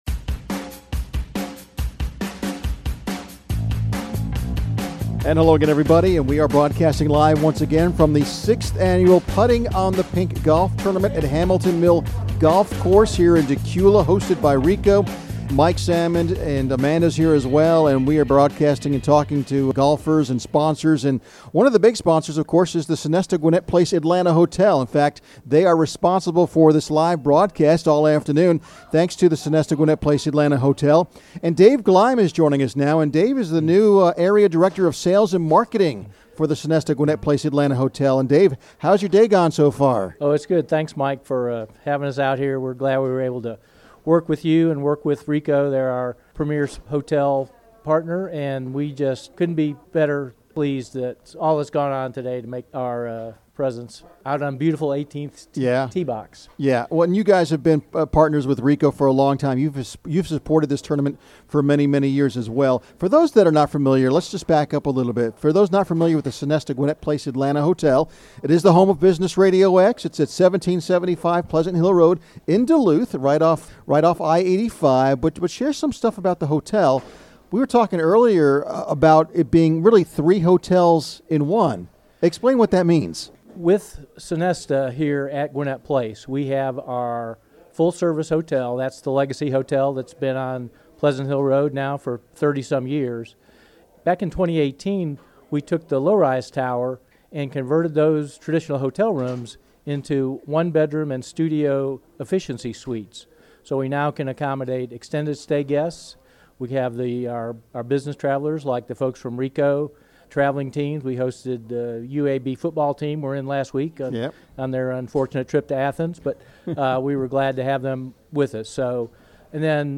Thanks to a generous contribution by the Sonesta Gwinnett Place Atlanta Hotel, Business RadioX was on site again this year to broadcast live and conduct interviews with event organizers, corporate sponsors, and golfers.